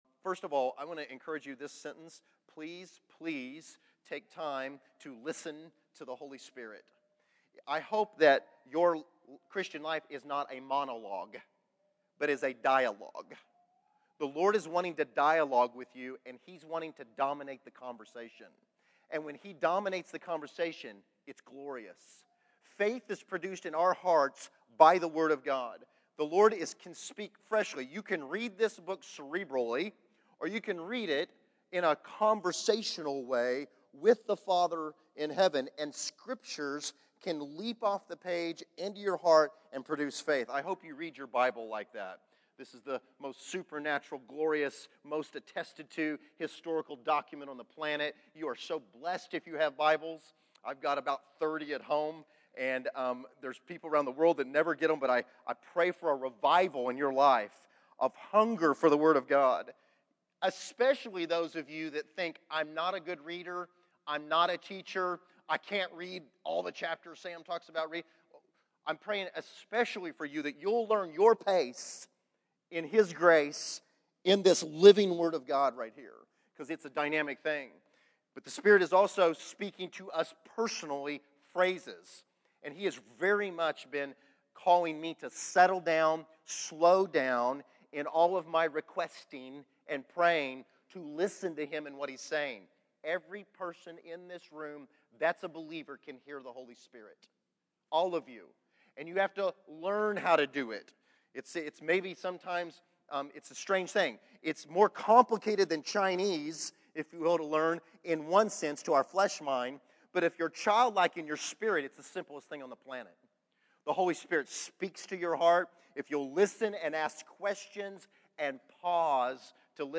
Exhortation: Hearing the Spirit